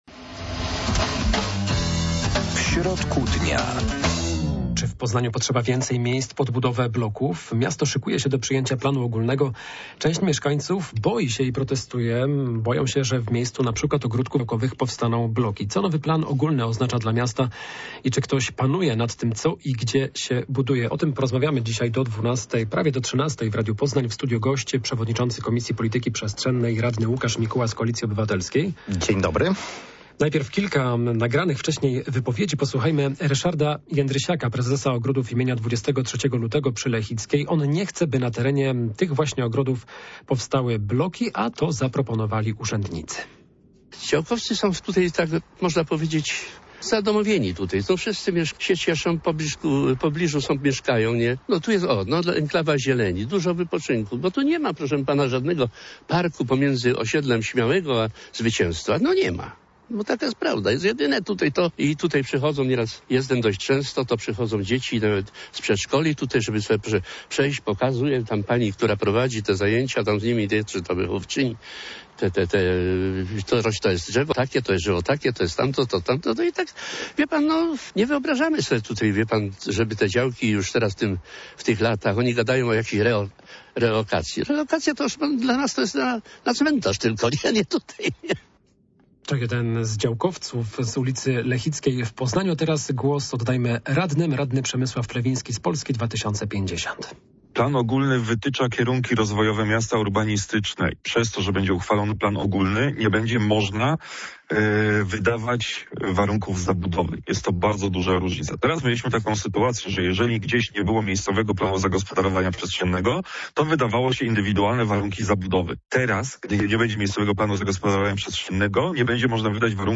Gościem programy będzie przewodniczący komisji polityki przestrzennej radny Łukasz Mikuła.